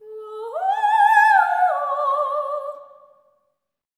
OPERATIC01-L.wav